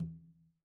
Conga-HitN_v1_rr2_Sum.wav